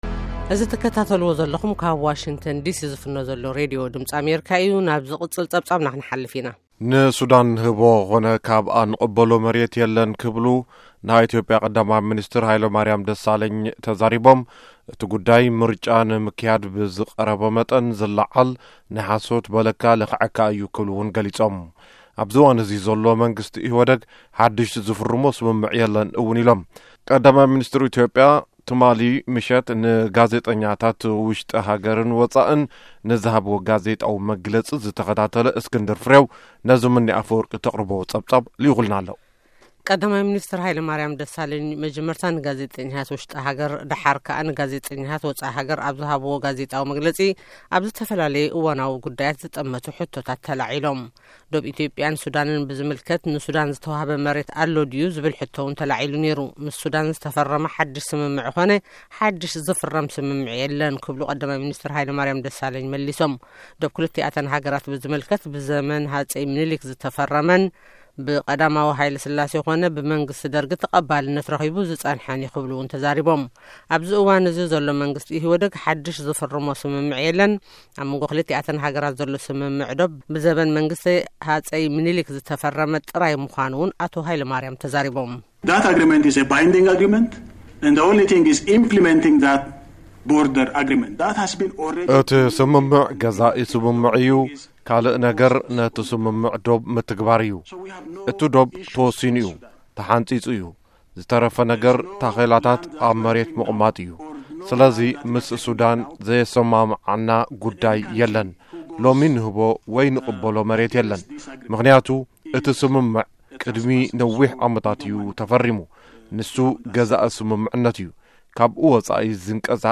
ቀዳማይ ሚኒስተር ሃይለማርያም ደሳለኝ ኣብ ጋዜጣዊ መግለፂ ካብ ዝበልዎ